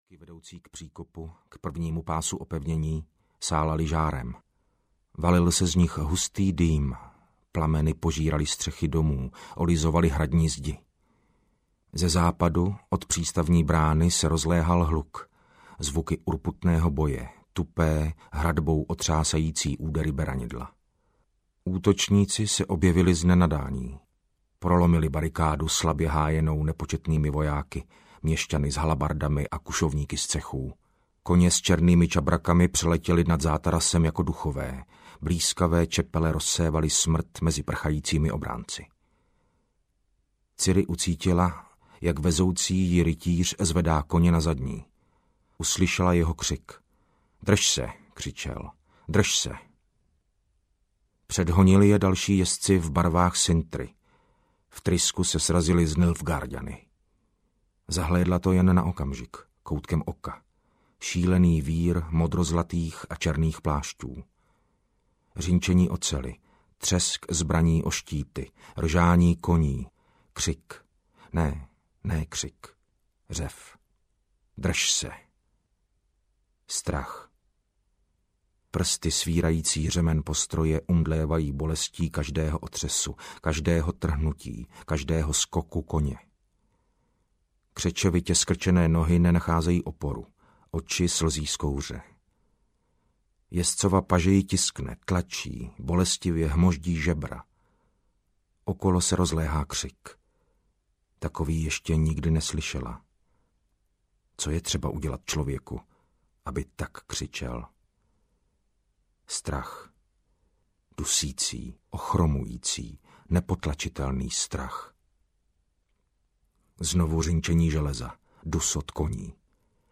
Audio knihaSága o Zaklínači
Ukázka z knihy
• InterpretMartin Finger